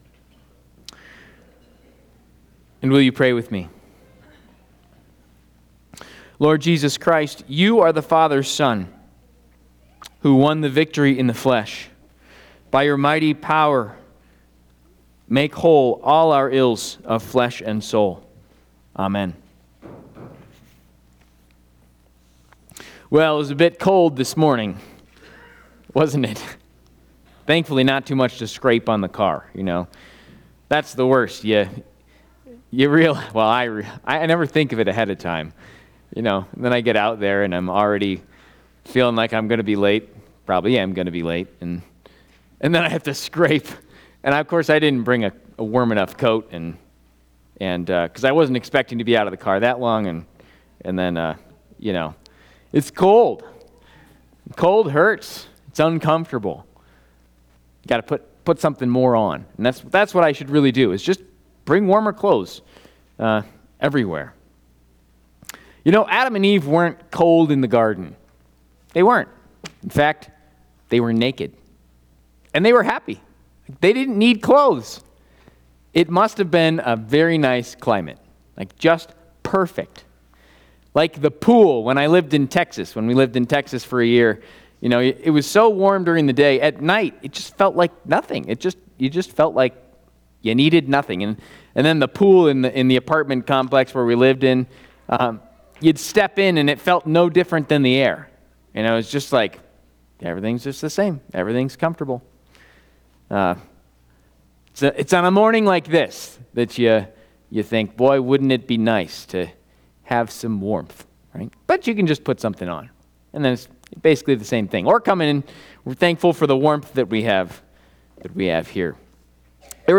First Sunday in Advent&nbsp